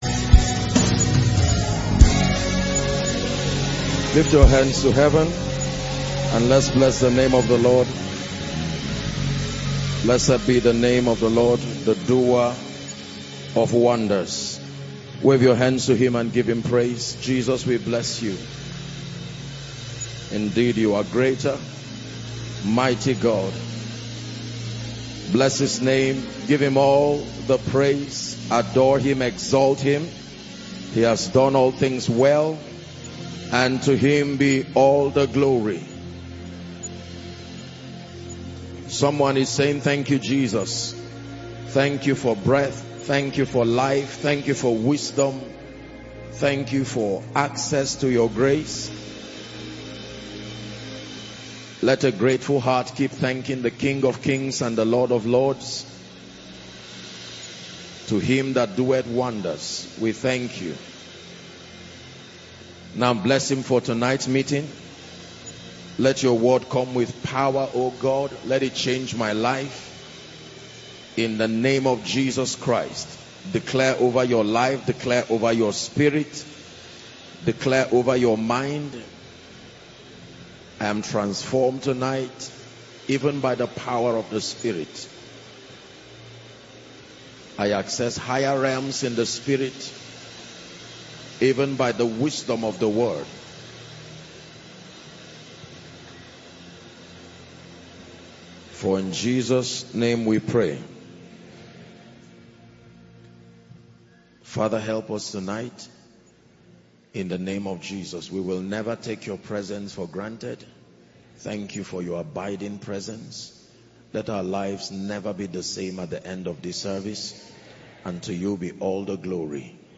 There are always new dimensions for believers, but there are prices to pay. In this Sermon